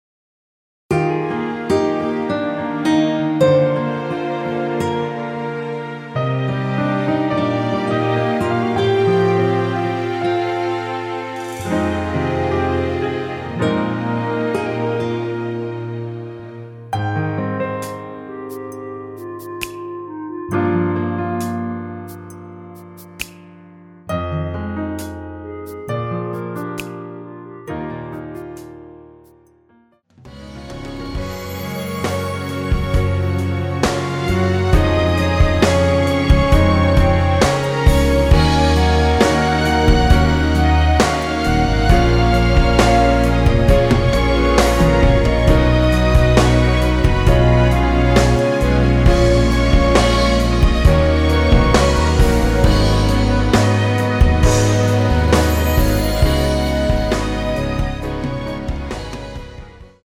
(-1) 내린 멜로디 포함된 MR 입니다.(미리듣기 참조)
Eb
앞부분30초, 뒷부분30초씩 편집해서 올려 드리고 있습니다.
중간에 음이 끈어지고 다시 나오는 이유는